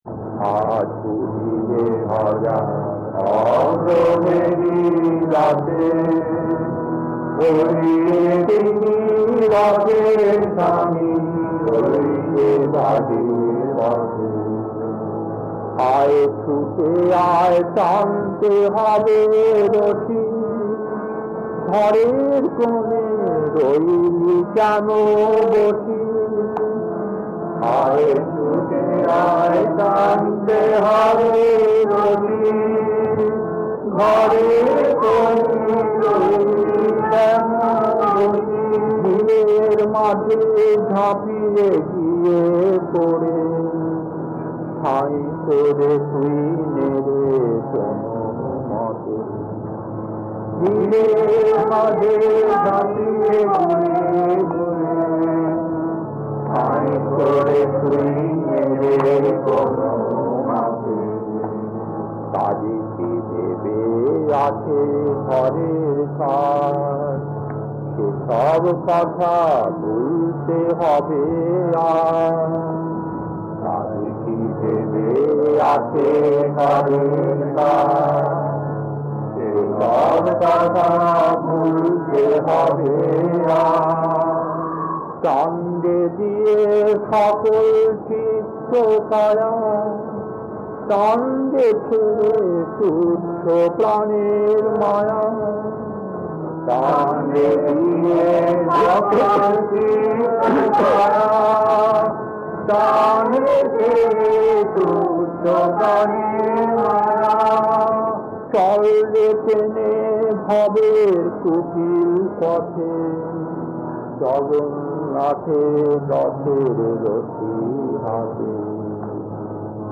Kirtan A1-1 Rockdale early days, 63 minutes 1.